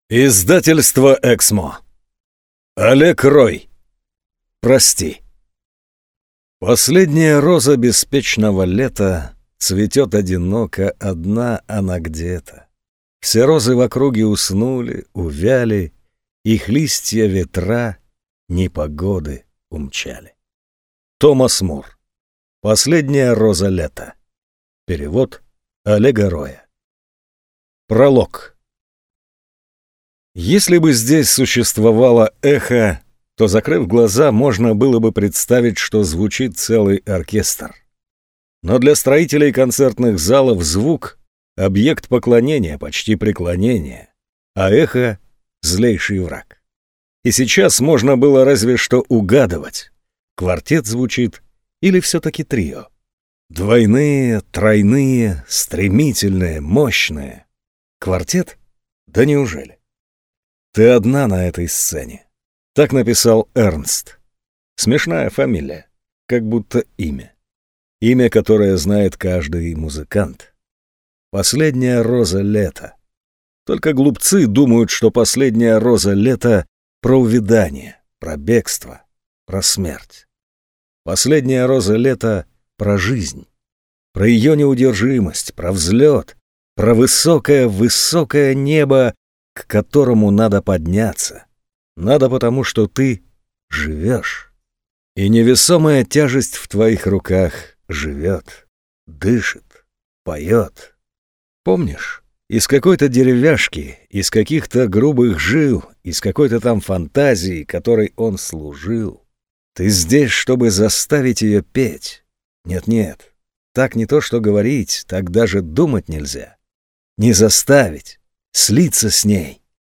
Аудиокнига Прости